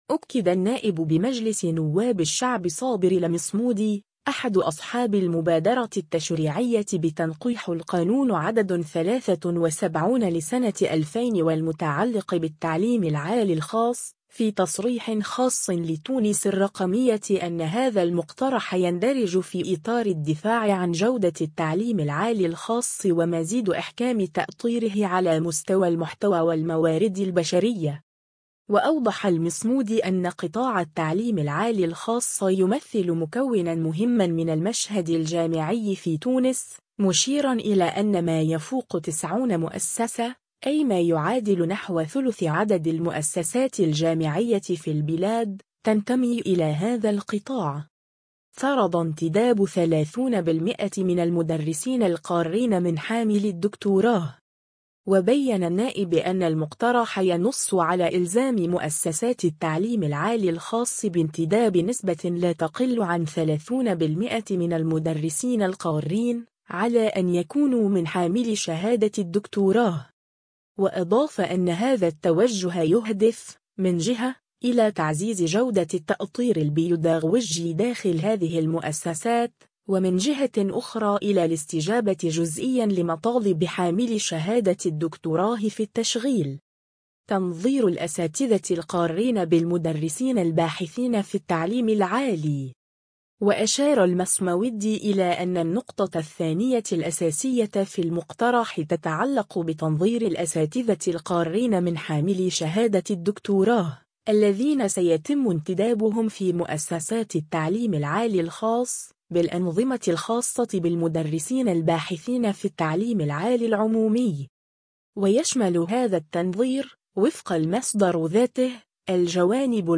أكد النائب بمجلس نواب الشعب صابر المصمودي، أحد أصحاب المبادرة التشريعية بتنقيح القانون عدد 73 لسنة 2000 والمتعلق بالتعليم العالي الخاص، في تصريح خاص لـ”تونس الرقمية” أن هذا المقترح يندرج في إطار الدفاع عن جودة التعليم العالي الخاص ومزيد إحكام تأطيره على مستوى المحتوى والموارد البشرية.